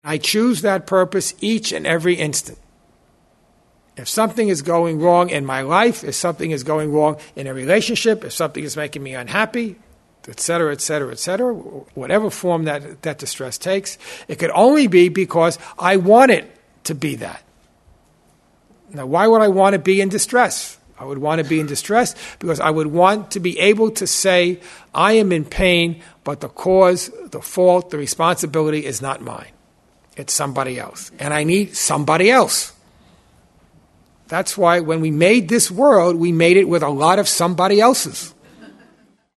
This workshop thus helps us understand our world, our relationships, and our experiences in terms of the underlying purpose of the ego.